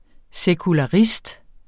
Udtale [ sεkulɑˈʁisd ]